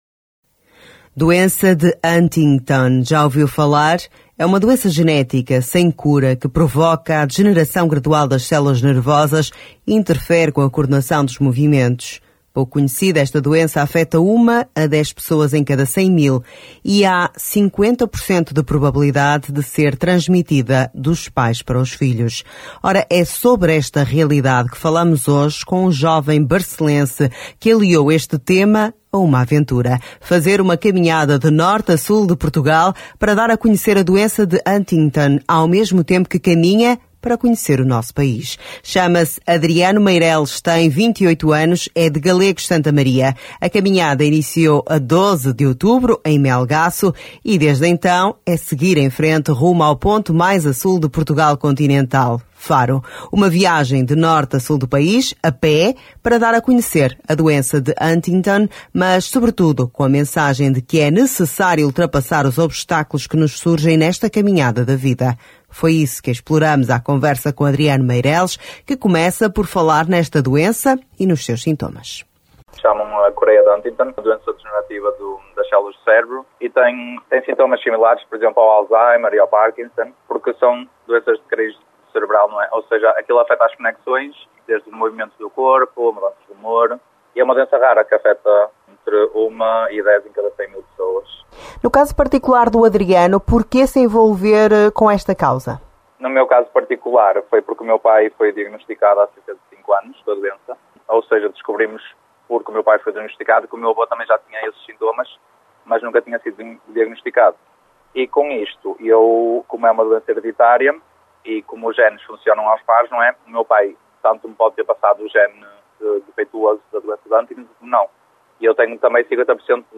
O objectivo é dar a conhecer a Doença de Huntington, mas sobretudo com a mensagem que é necessário ultrapassar os obstáculos que nos surgem nesta caminhada da vida. Isso mesmo conta à Rádio Barcelos em conversa